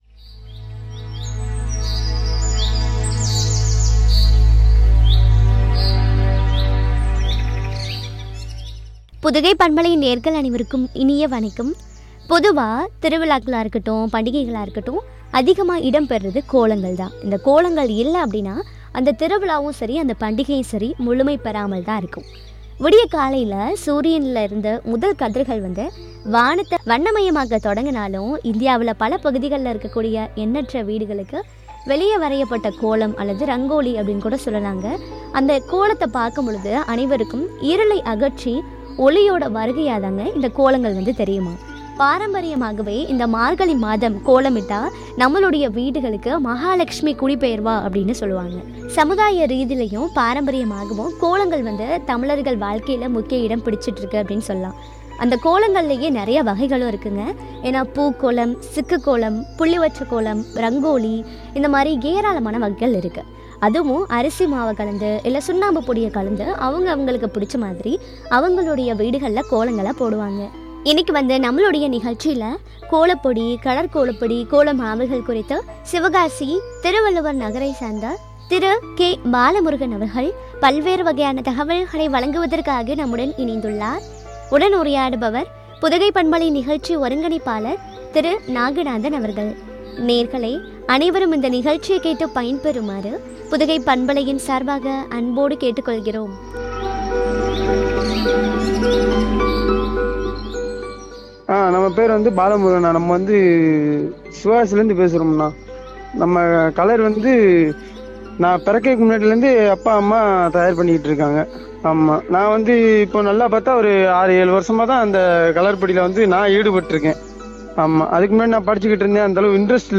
“வண்ணம் பொங்கும் பொங்கல்” குறித்து வழங்கிய உரையாடல்.